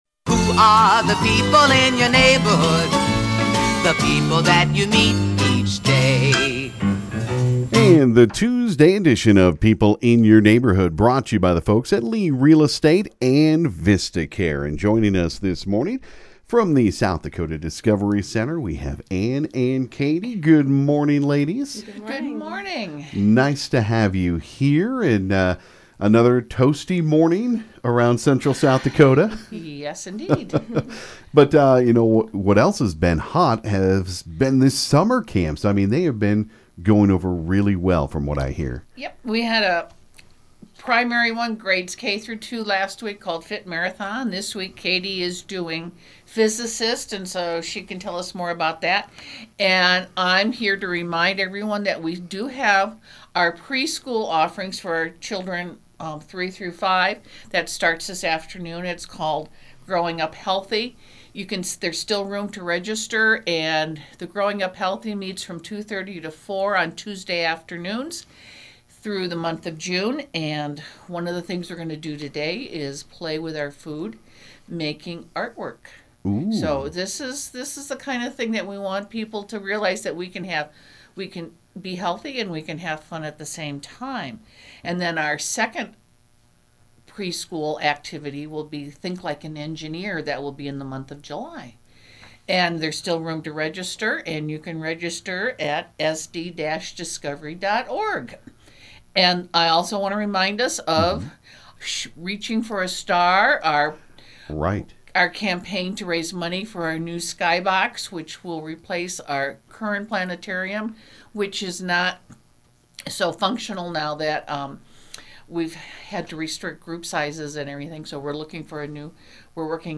stopped by KGFX this morning